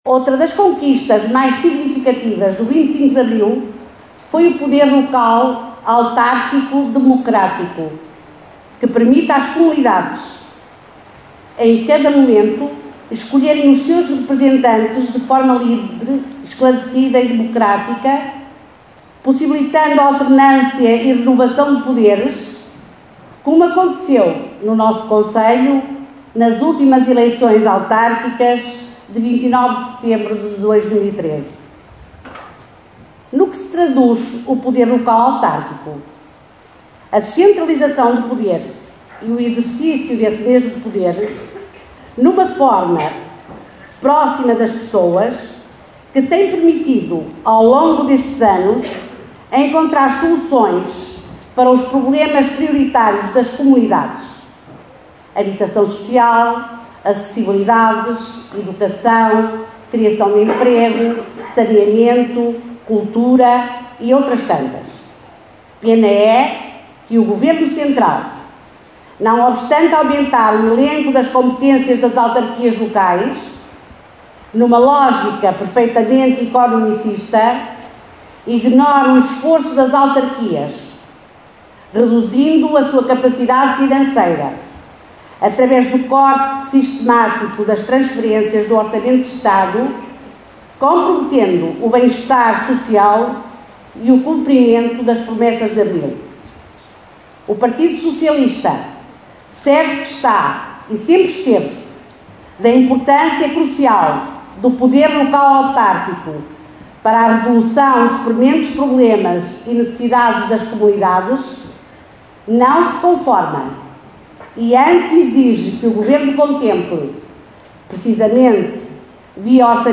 Foi com chuva que o 25 de Abril foi celebrado esta manhã em Caminha.